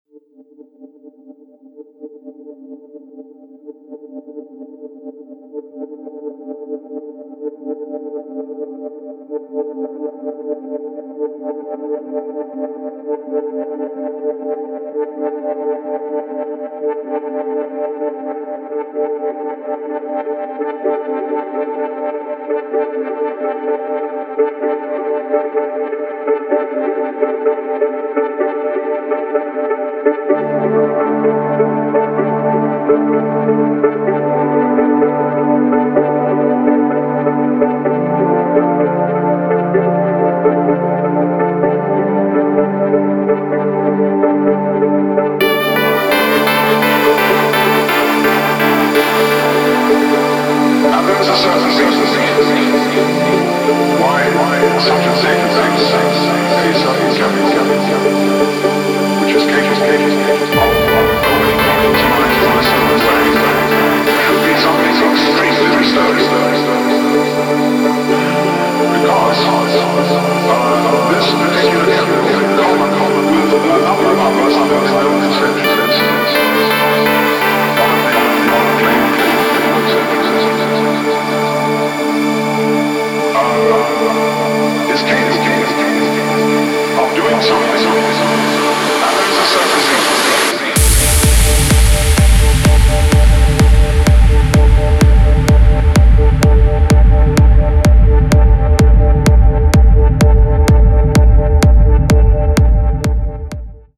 Интро без бита